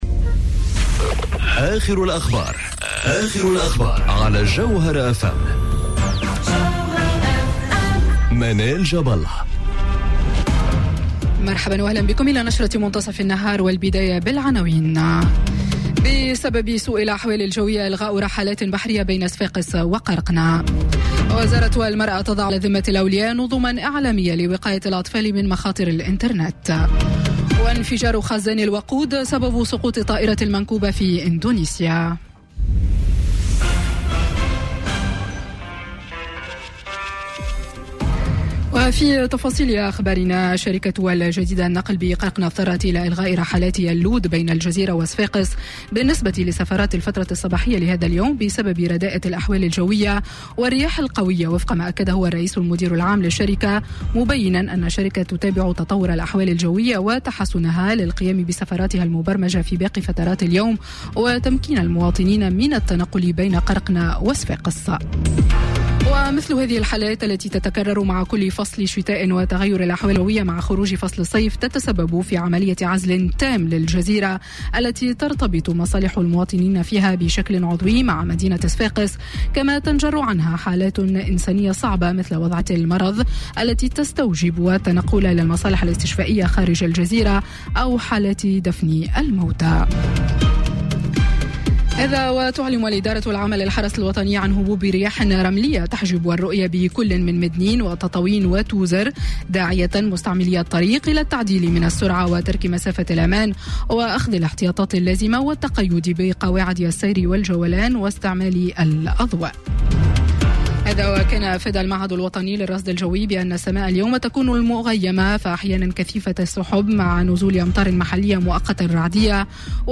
نشرة أخبار منتصف النهار ليوم الإثنين 29 أكتوبر 2018